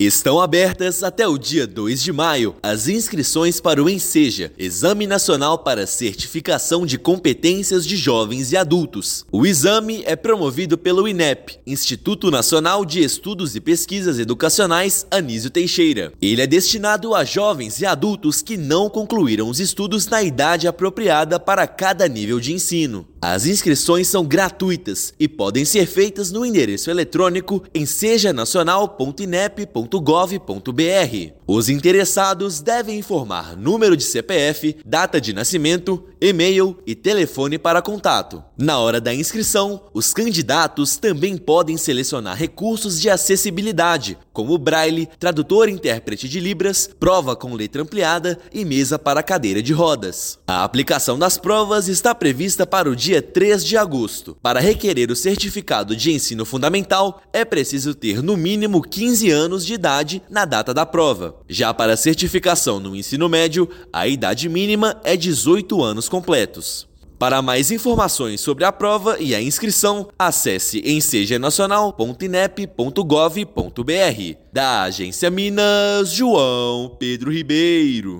Avaliação é destinada a estudantes que não concluíram os estudos na idade apropriada. Ouça matéria de radio.